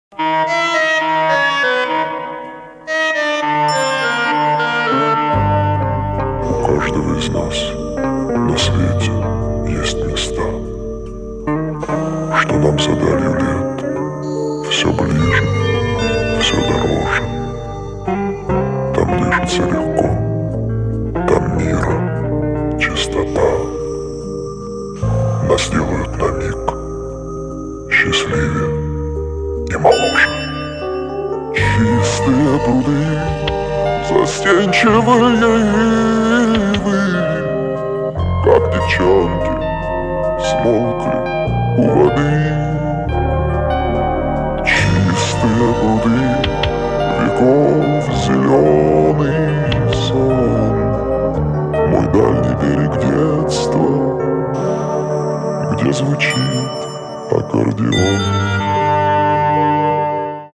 • Качество: 320, Stereo
спокойные
90-е
медленные
цикличные